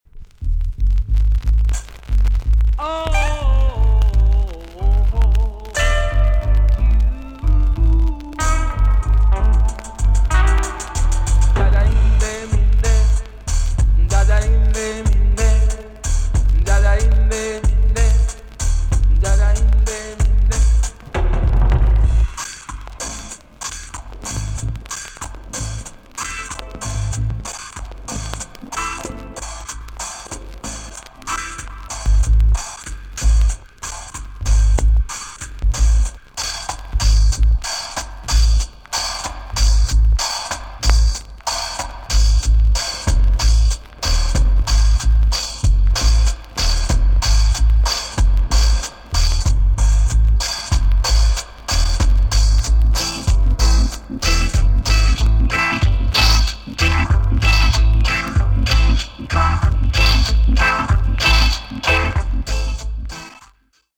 TOP >REGGAE & ROOTS
B.SIDE Version
VG+~EX- 少し軽いチリノイズが入りますが良好です。